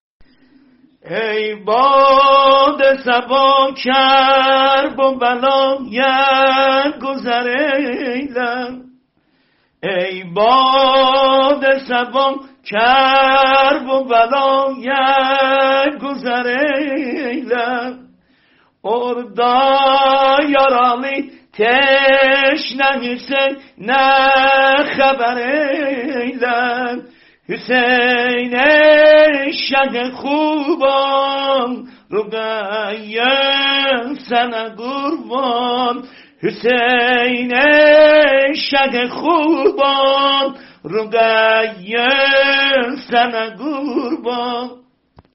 متن شعر ترکی زبانحال زهرای سه ساله(س) در ویرانه شام -(ایلر منه چوخ محنت غربت اثر عمه )